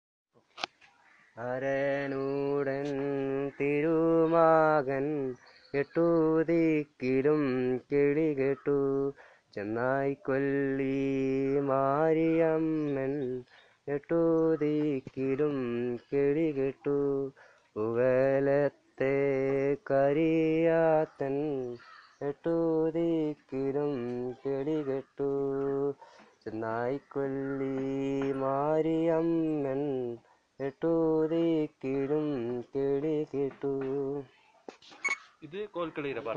Performance of folk song